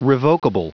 Prononciation du mot revokable en anglais (fichier audio)
Prononciation du mot : revokable